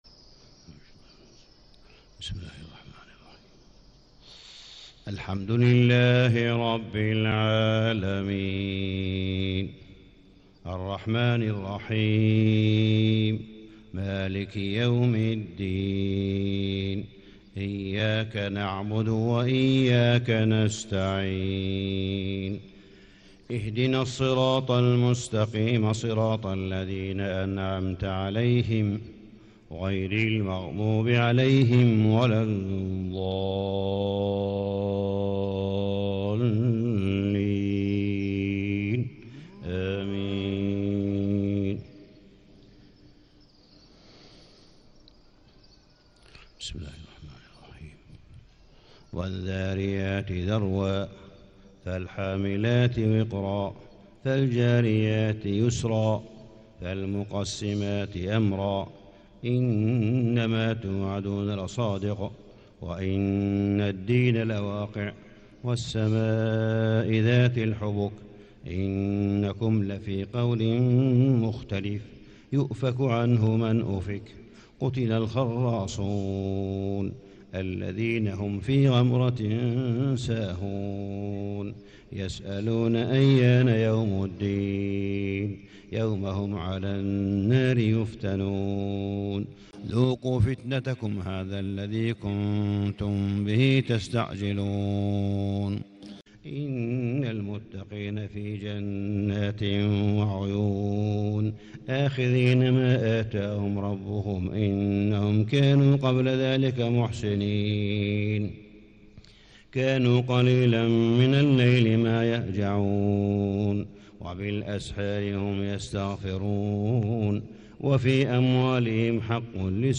صلاة الفجر 6 ربيع الأول1437هـ سورة الذاريات كاملة > 1437 🕋 > الفروض - تلاوات الحرمين